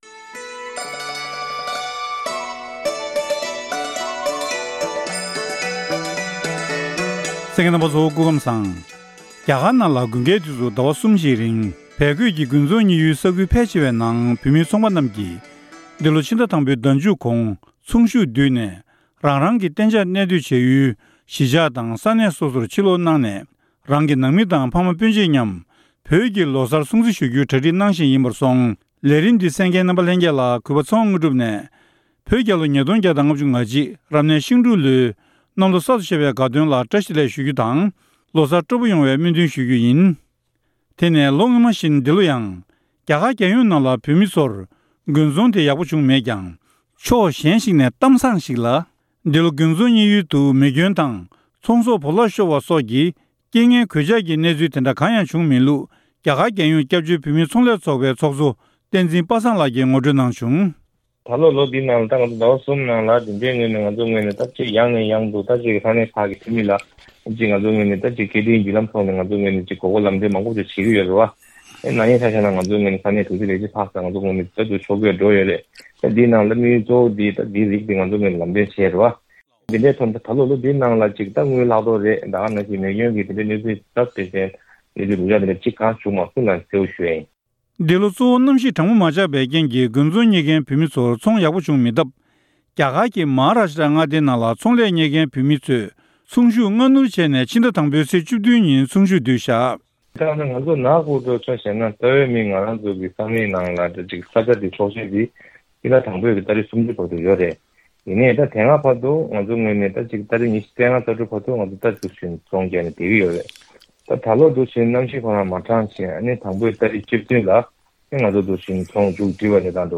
བཀའ་འདྲི་ཞུས་ནས་ཕྱོགས་སྒྲིགས་ཞུས་པ་ཞིག་གསན་རོགས་གནང་།།